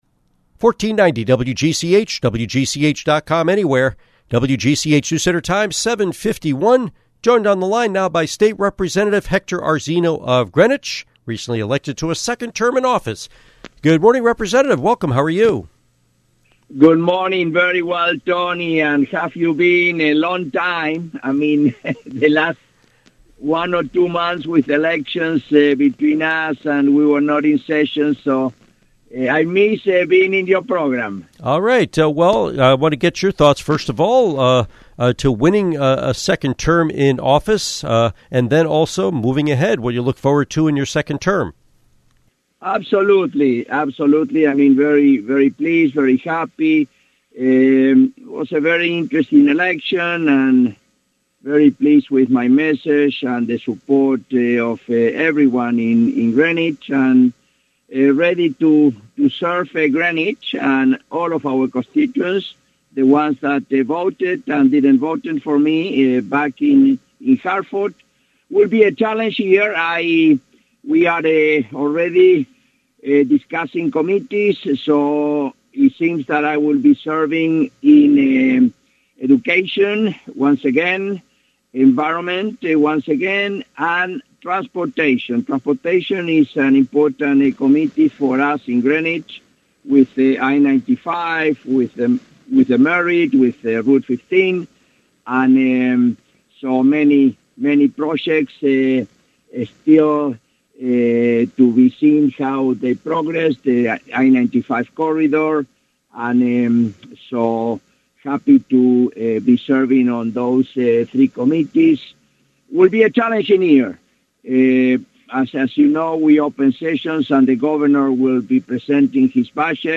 Interview with State Representative Hector Arzeno